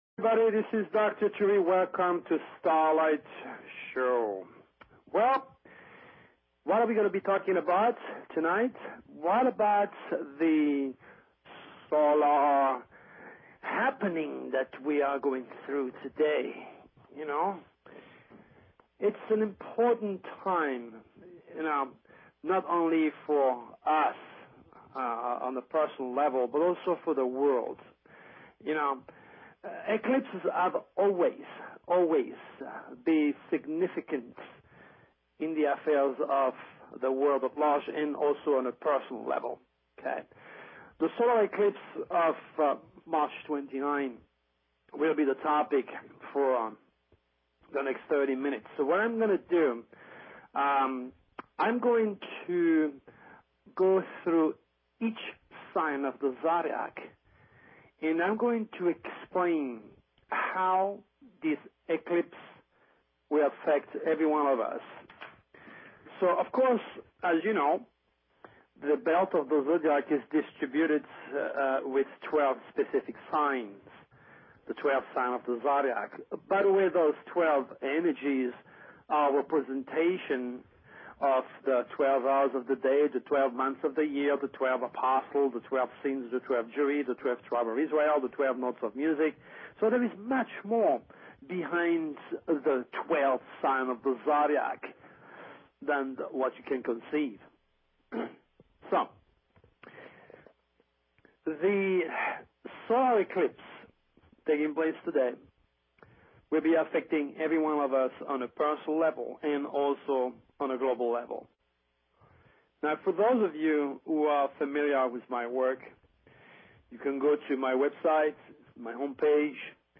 Talk Show Episode, Audio Podcast, Starlight_Radio and Courtesy of BBS Radio on , show guests , about , categorized as